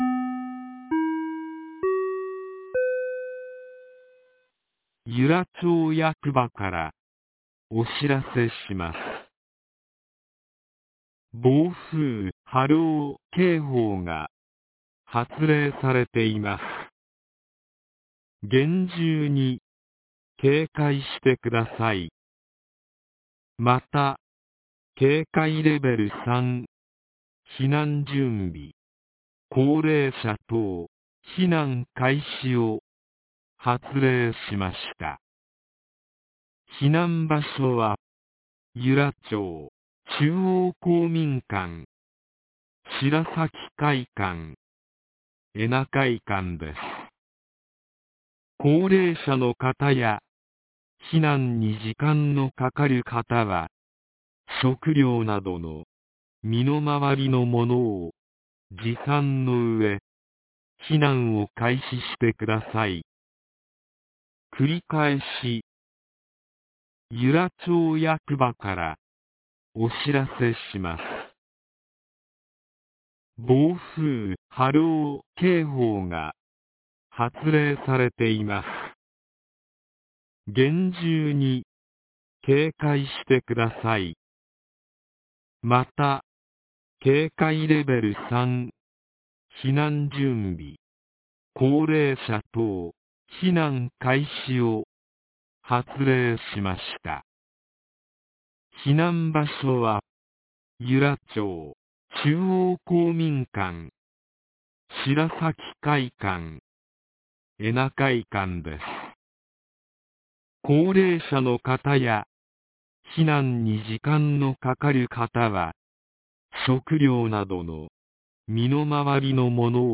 2019年10月12日 07時33分に、由良町から全地区へ放送がありました。